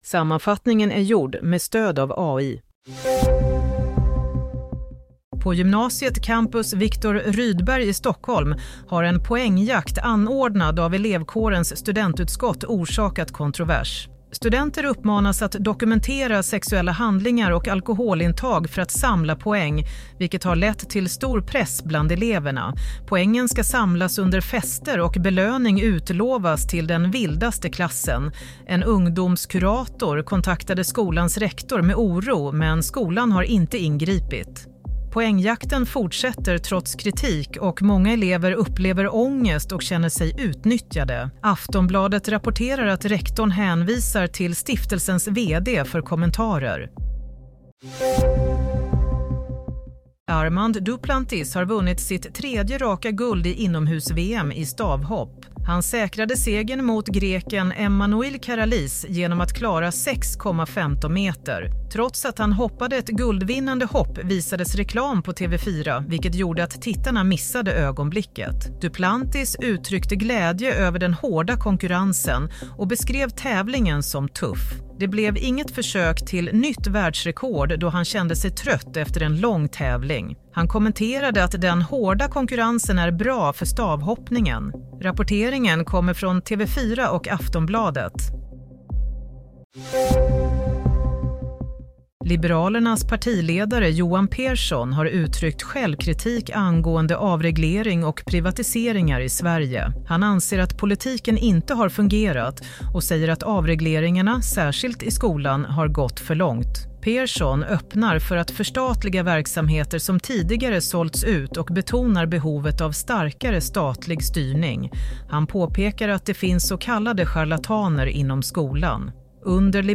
Play - Nyhetssammanfattning 22 mars klockan 15.45